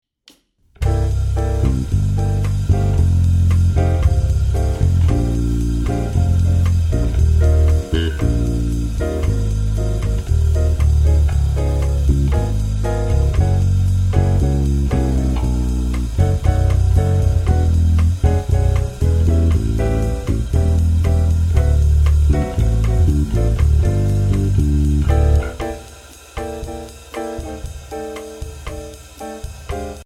Voicing: Electric Bass